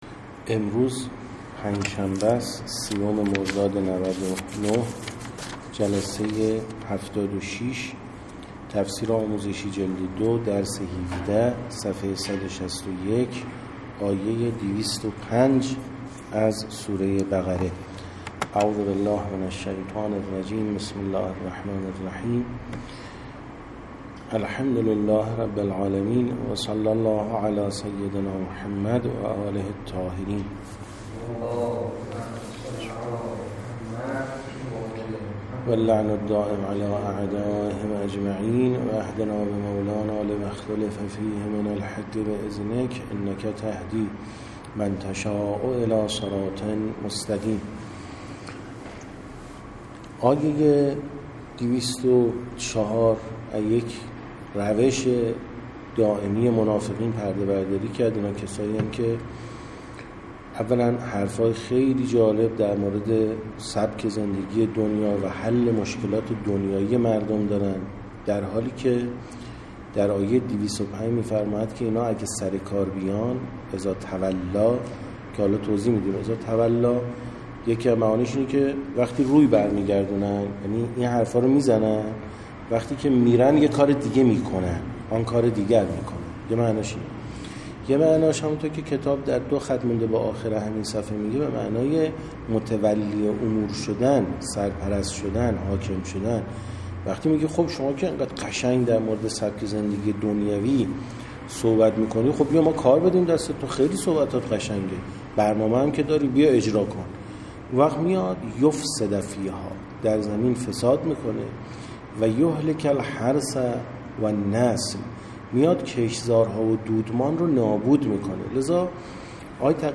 76درس17ج2تفسیرآموزشی-ص161تا164-آیه205و206بقره.MP3